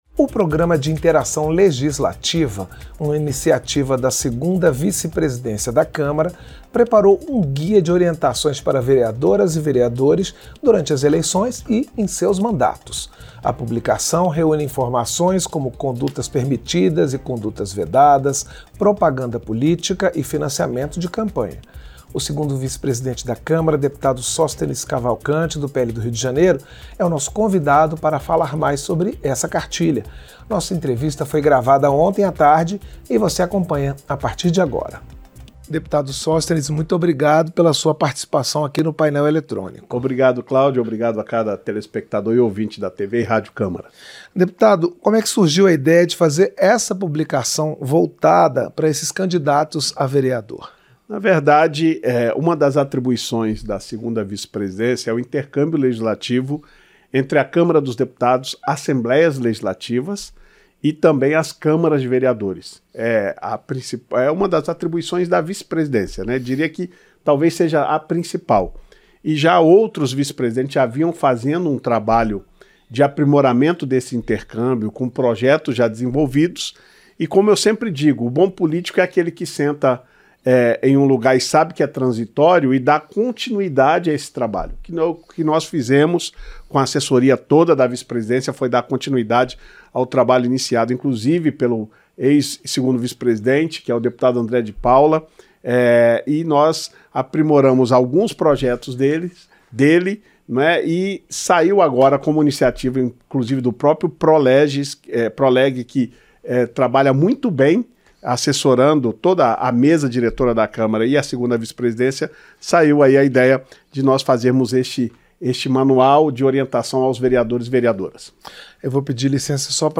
Entrevista - Dep. Sóstenes Cavalcante (PL-RJ)